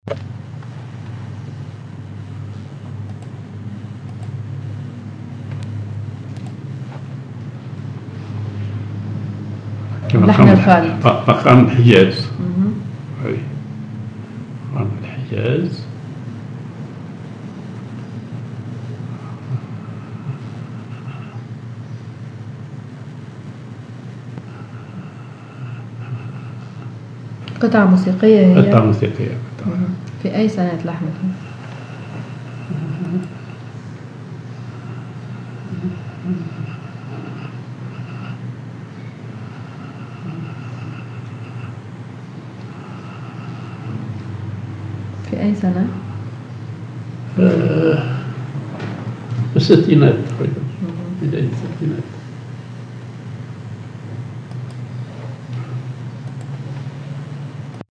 Maqam ar نهاوند
معزوفة